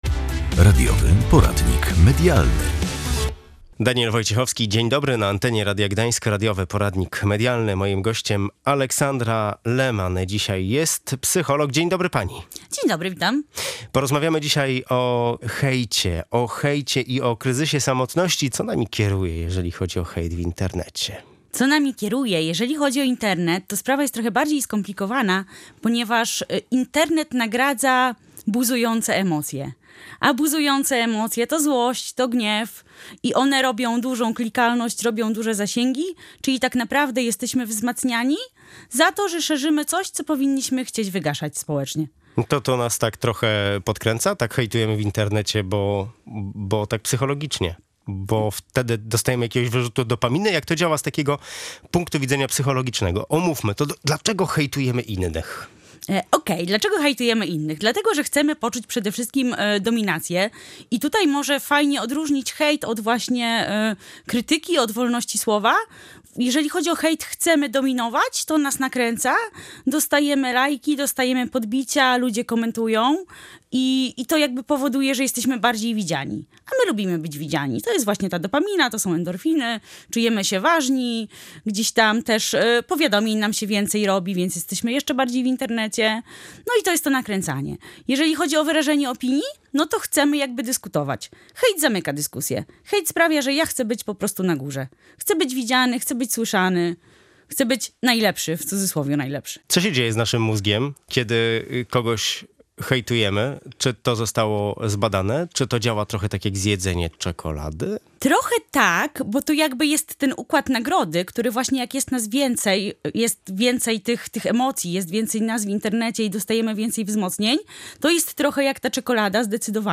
Posiadanie znajomych w sieci nie chroni przed izolacją. Rozmawiamy z psycholog